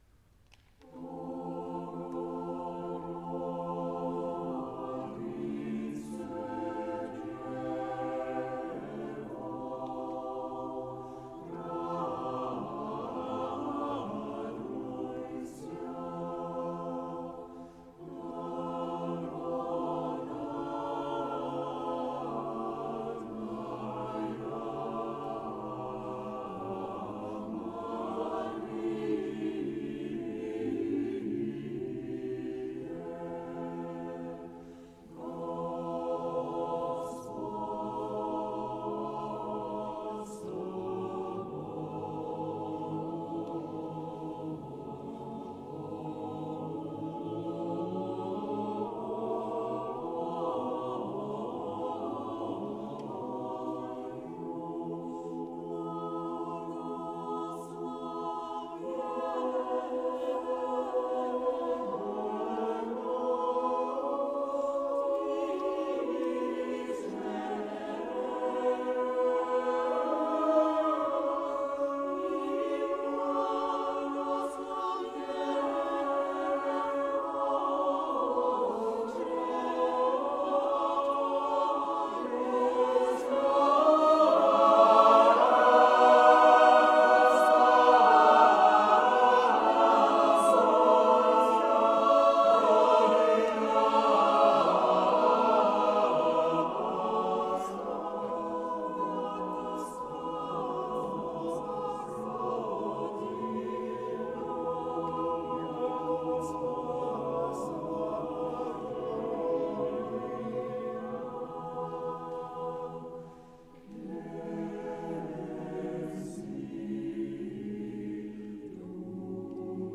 Klang - Chor Vokalensemble Capella Moguntina, Mainz